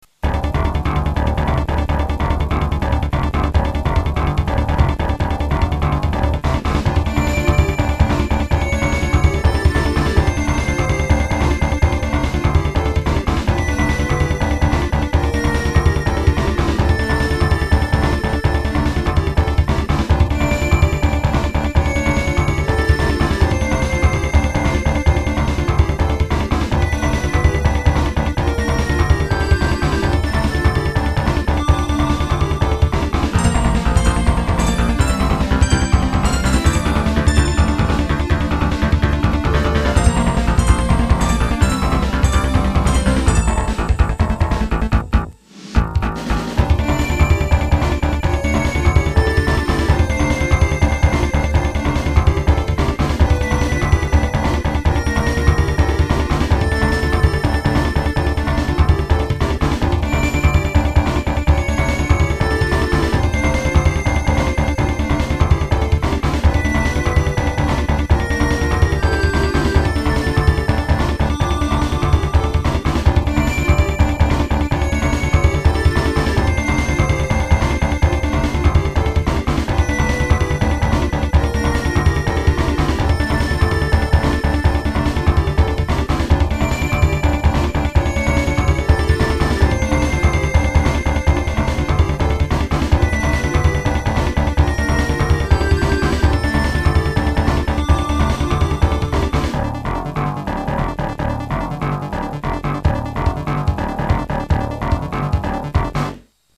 ※データは全てSC-88Pro専用です